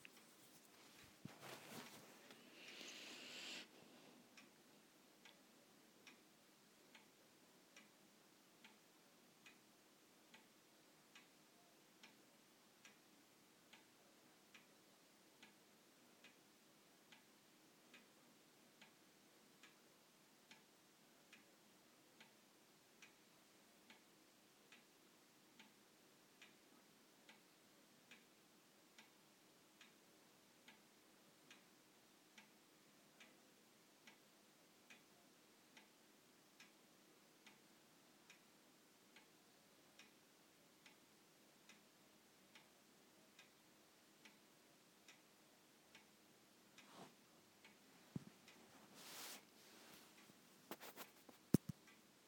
A very soothing sound